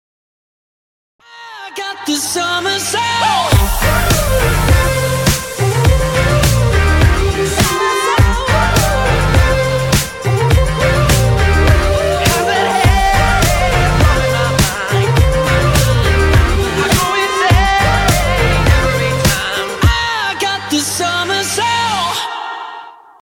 • Качество: 320, Stereo
поп
позитивные
indie pop